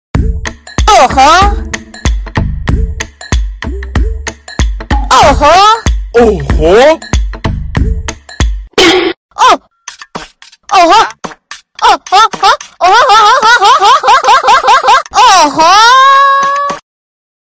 SFX哦豁哦吼音效下载
SFX音效